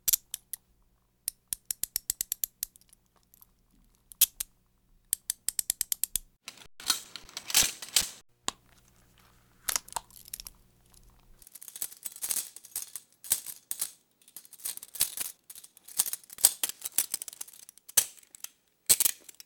جلوه های صوتی
دانلود صدای دستبند زدن پلیس از ساعد نیوز با لینک مستقیم و کیفیت بالا